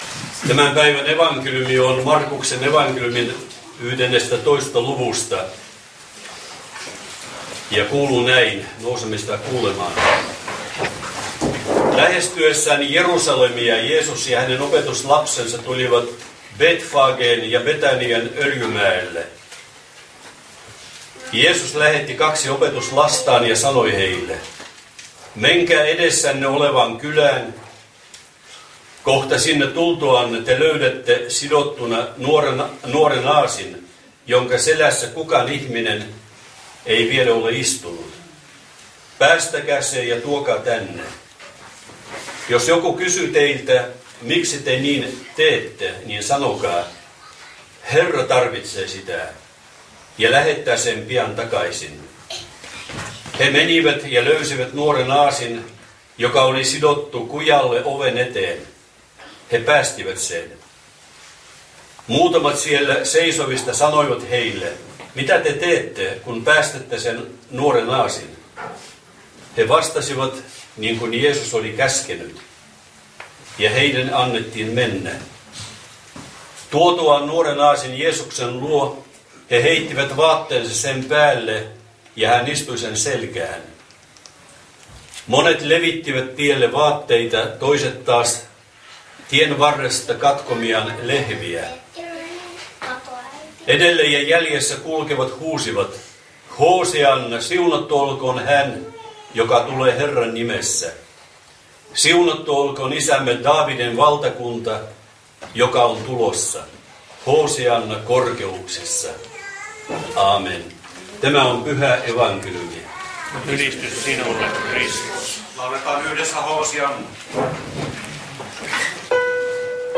Lappeenranta